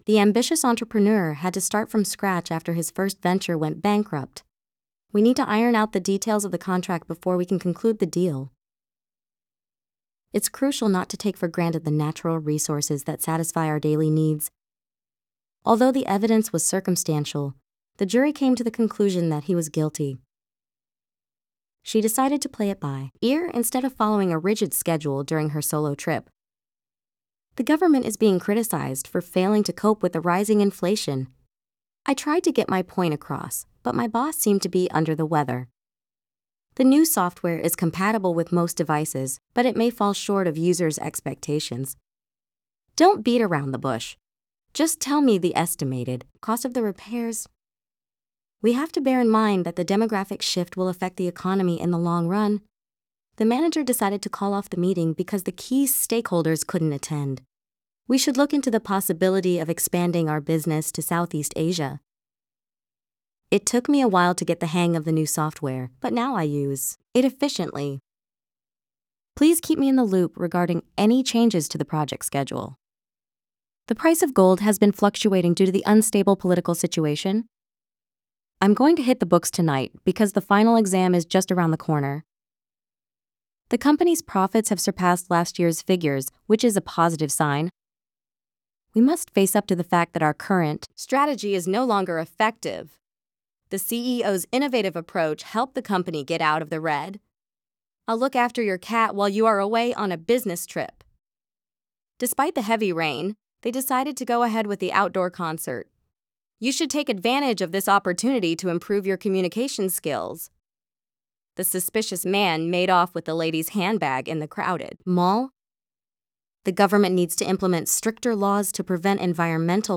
なんかパズルみたいで，ちょっと面白くなってきたので，geminiを使って，少し作ってみました．まず，C1レベルは，知らない単語ばっかり出てくる感じなので，「英語でよく使われる単語の上位5000語，イディオムの上位1000語を網羅していてるB2レベルの文を作るとしたらいくつ必要ですか？」ときいたら，2000くらいでできると言うので，でも，いっぺんには無理で50ずつならということで作ってもらいました．音声は，google TTSだとファイルでダウンロードできないので，Adobeのものを使いましたが，（所々イントネーションが変なところはありますが）
これで充分な気がします．